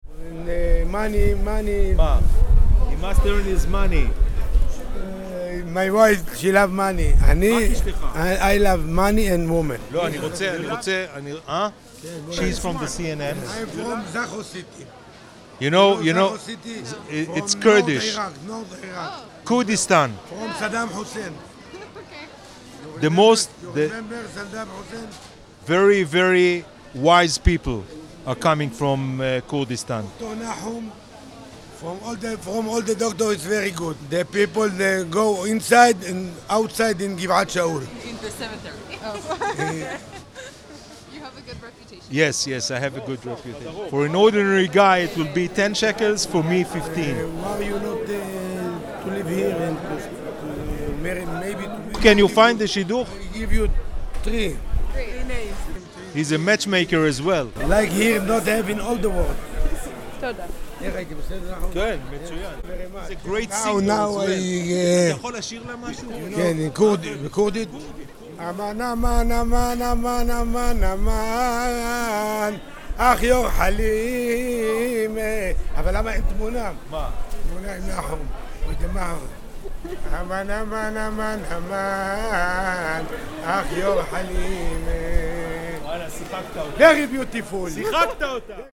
click on the audio below to hear this guy singing to us